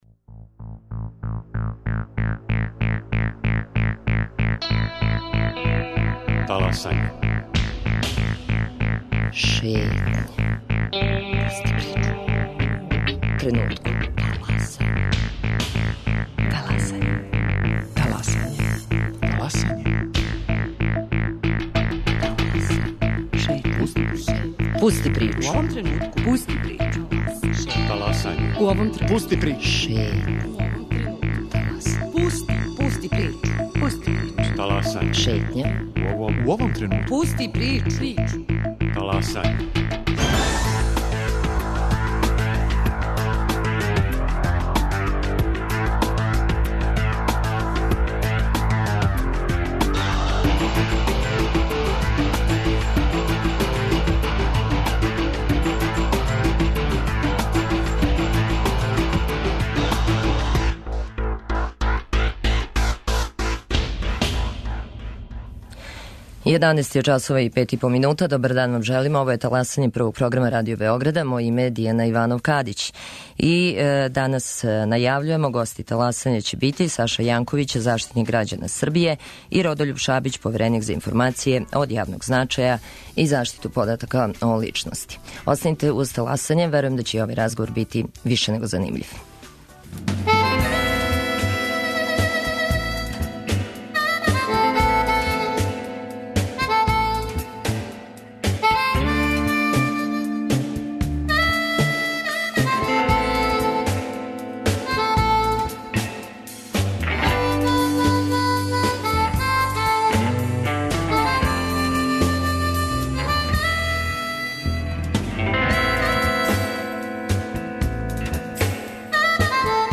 О томе колико често политичари и јавна управа игноришу њихове препоруке за Таласање говоре Саша Јанковић, заштитник грађана Србије и Родољуб Шабић, повереник за информације од јавног значаја и заштиту података о личности.